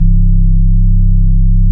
CORTEX BASS.wav